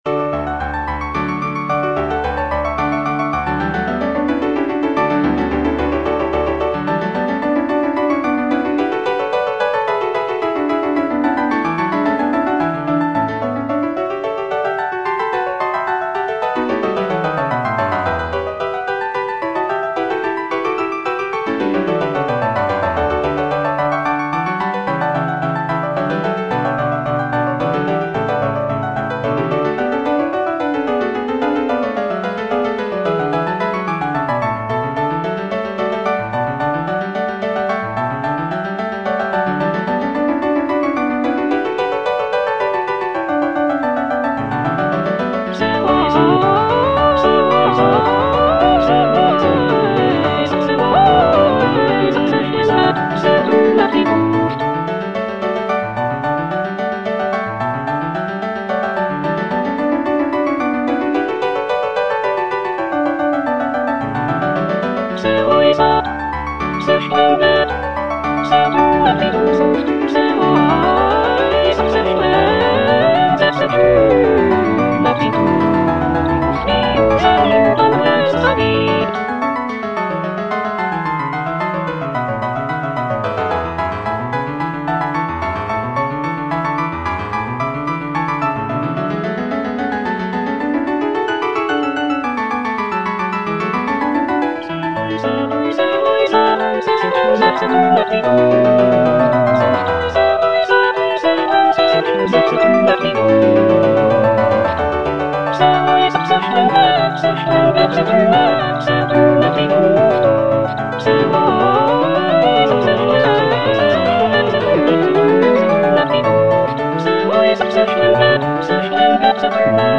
The cantata features a dialogue between the wind god Aeolus and the river god Alpheus, celebrating the prince's virtues and rulership. The music is lively and celebratory, with intricate counterpoint and virtuosic vocal lines.